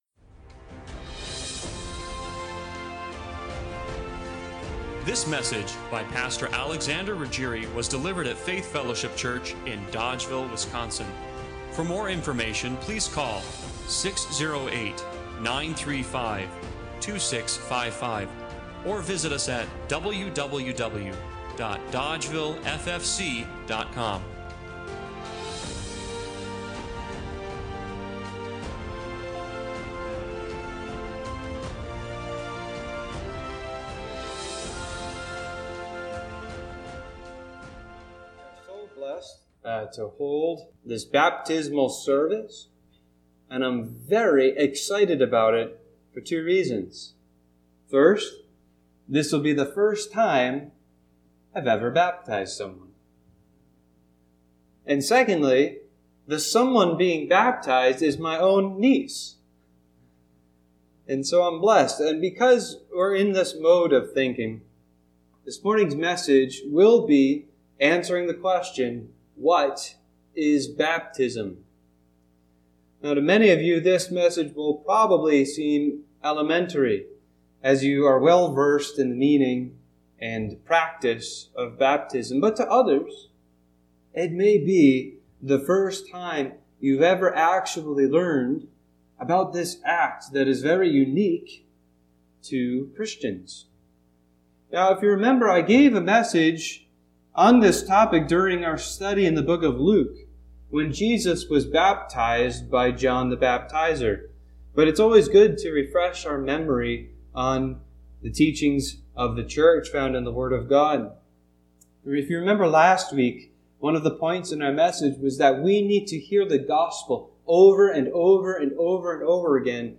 Acts 2:32-41 Service Type: Sunday Morning Worship Bible Text